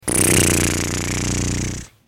دانلود صدای هلیکوپتر 4 از ساعد نیوز با لینک مستقیم و کیفیت بالا
جلوه های صوتی